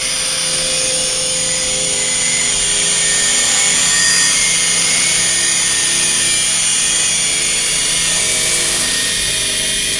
Скрипучий беспилотник:
drone4.wav